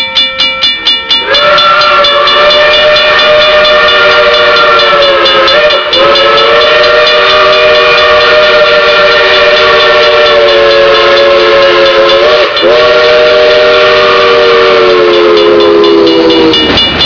whistles for Toad Lane